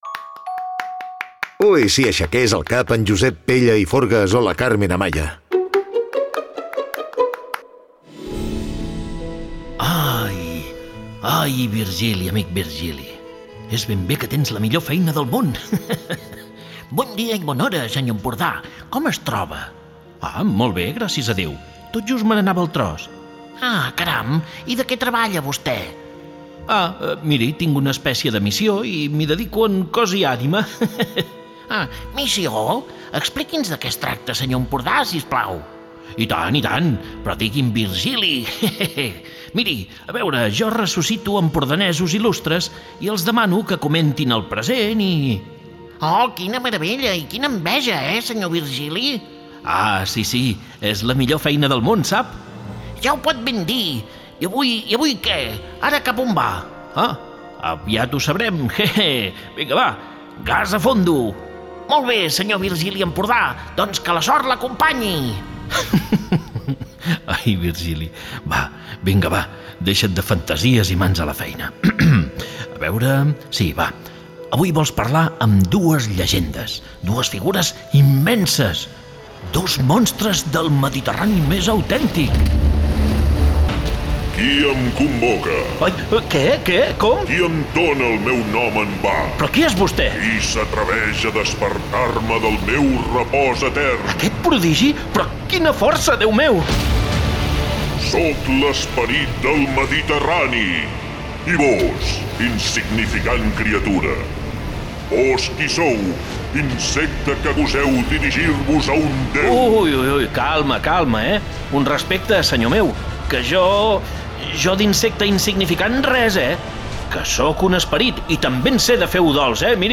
al principi en un andalús tancadíssim, però de seguida en un català andalusitzat